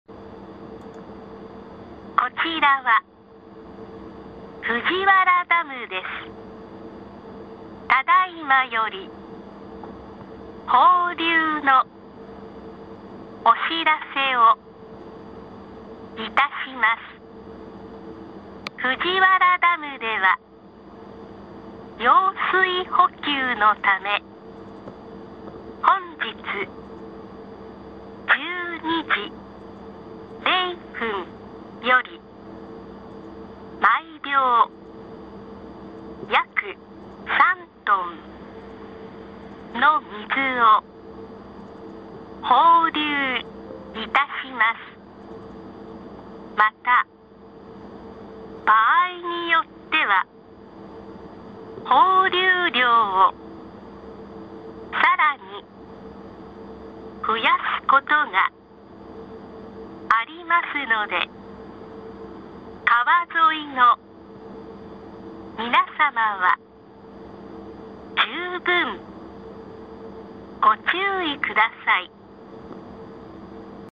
スピーカー、サイレン、警報車による警報を行います。
スピーカー（疑似音）の音